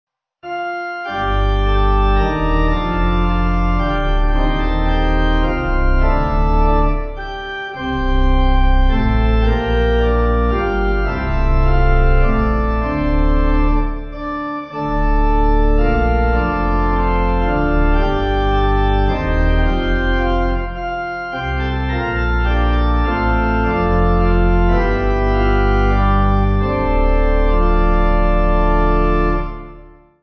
Organ
(CM)   5/Dm